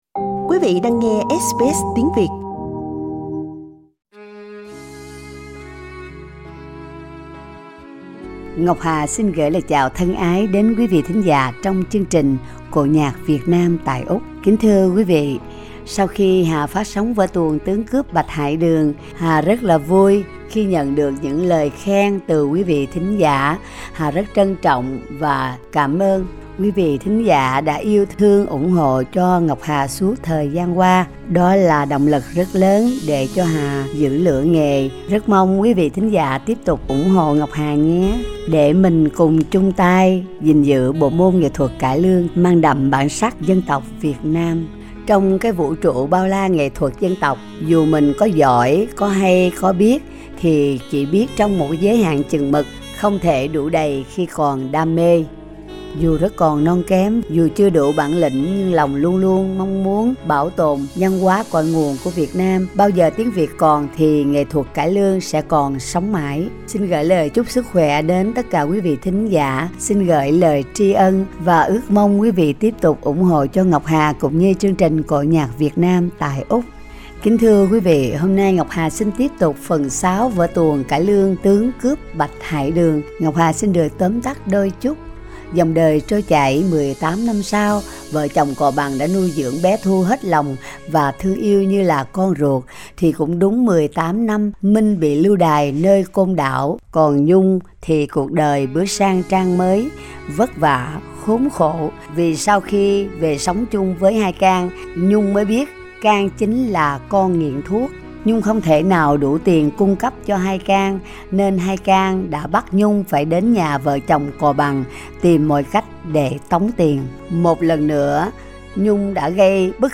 Cổ Nhạc Việt Nam: vở cải lương 'Bạch Hải Đường' phần 6
Xin mời quý vị cùng thưởng thức tiếp Phần 6 vở tuồng cải lương 'Tướng cướp Bạch Hải Đường' do anh chị em nghệ sĩ Úc châu Sydney trình diễn.